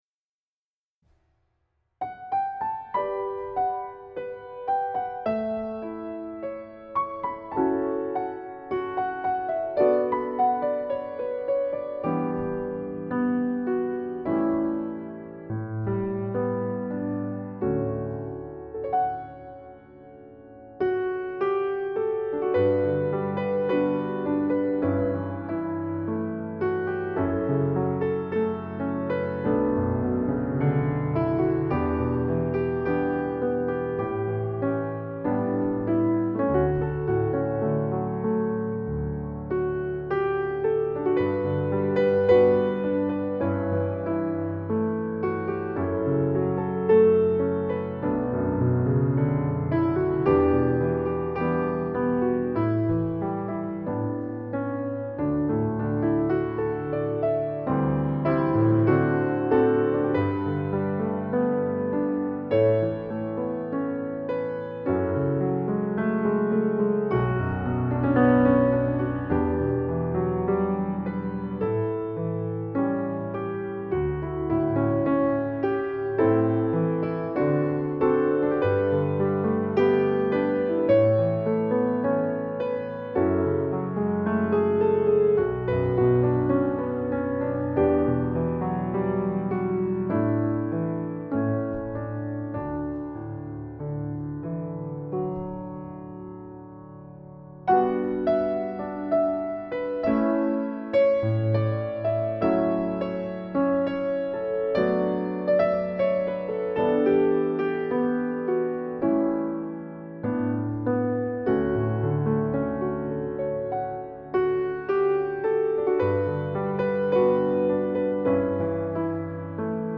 Piano Remix without Bird Sounds